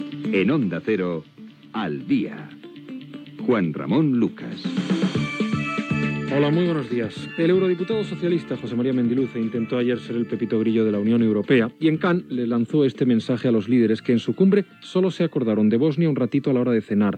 Careta del programa i inici de l'informatiu
Informatiu